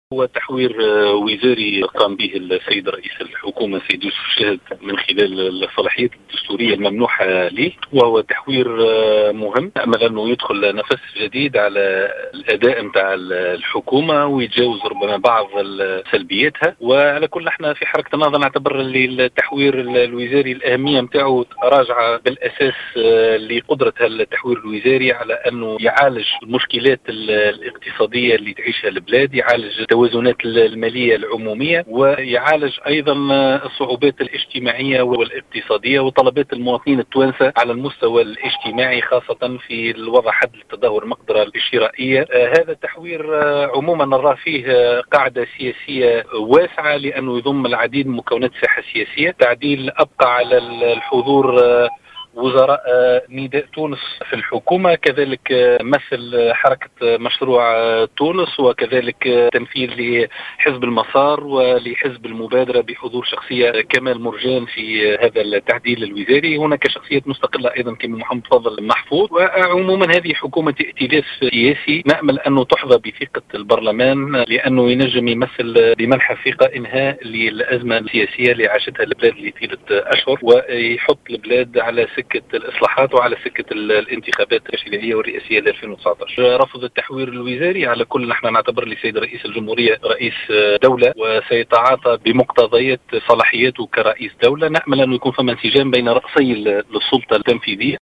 أكد الناطق باسم حركة النهضة عماد الخميري في تصريح للجوهرة "اف ام" أن التحوير الوزاري الذي أعلنه رئيس الحكومة يوسف الشاهد مساء أمس هو تحوير مهم وسيدخل نفسا جديدا على عمل الحكومة وأدائها ويتجاوز بعض سلبياتها.